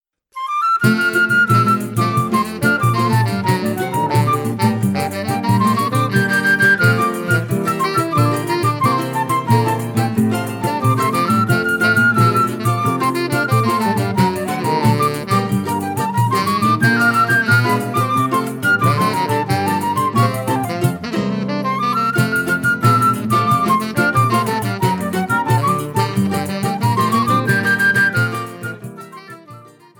flute
tenor saxophone